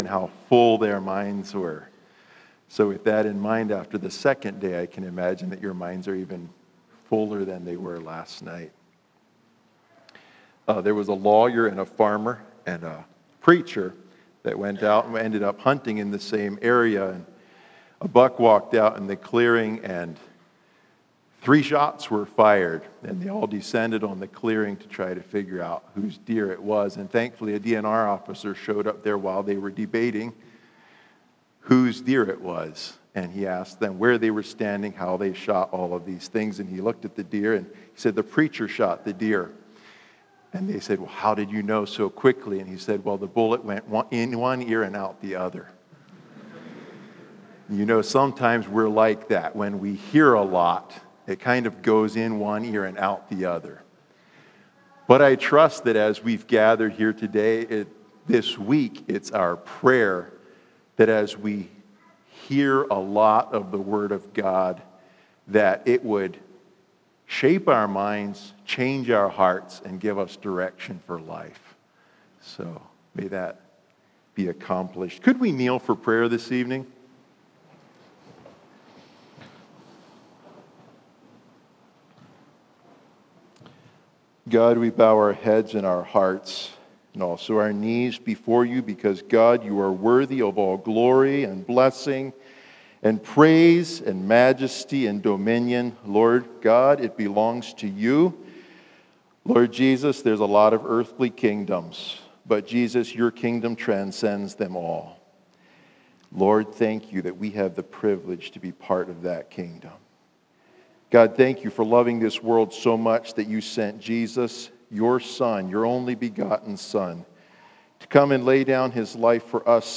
Youth Bible School 2025